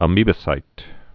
(ə-mēbə-sīt)